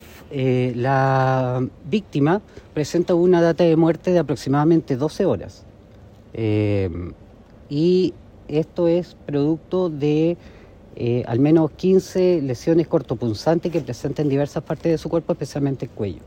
La Fiscalía Centro Norte, liderada en este procedimiento por el fiscal Manuel Zúñiga Rodríguez, agregó que la víctima fue encontrada con una data de muerte de 12 horas.